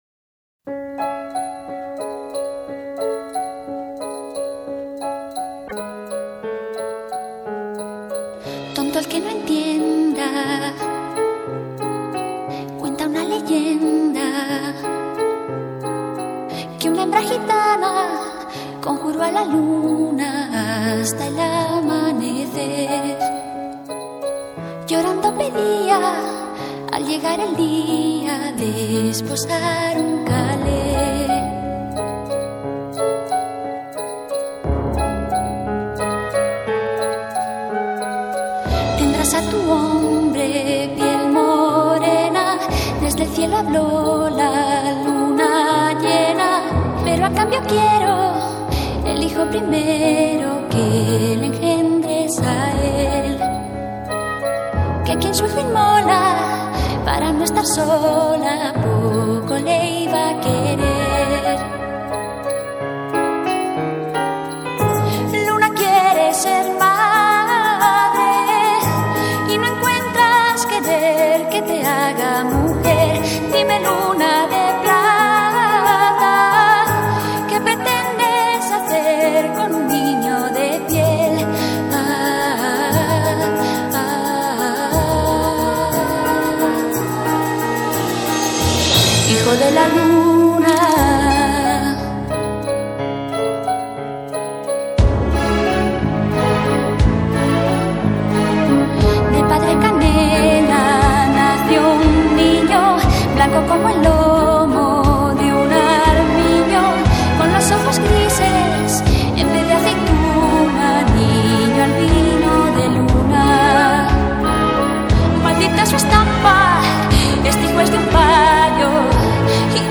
spanish rock group